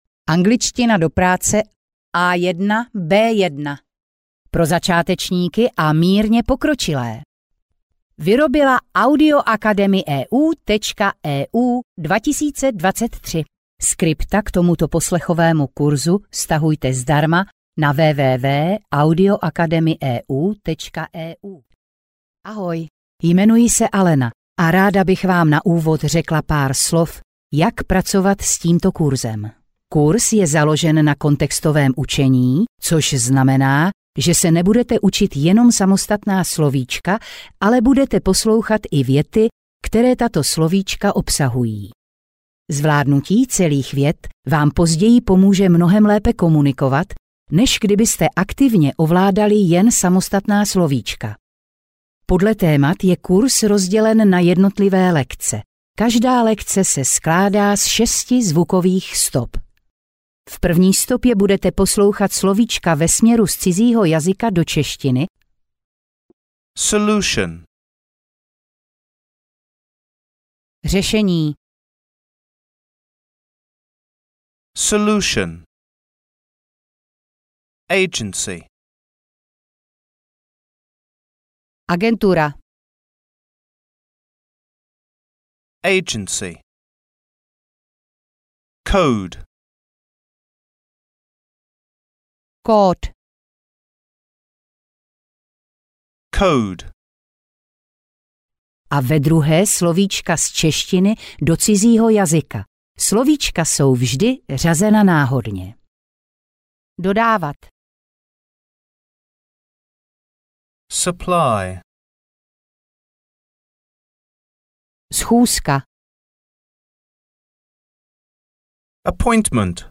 Angličtina do práce A1-B1 audiokniha
Ukázka z knihy